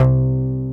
BASS+8AV.wav